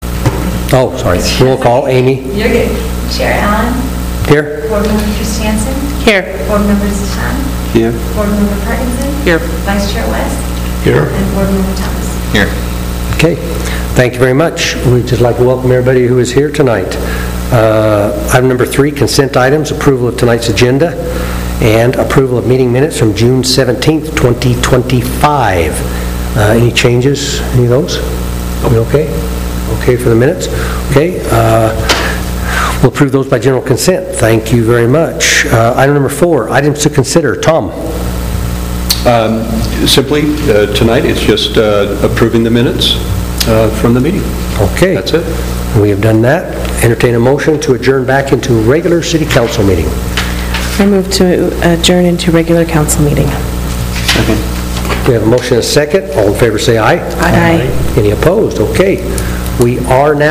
Redevelopment Agency Meeting Agenda
5249 South 400 East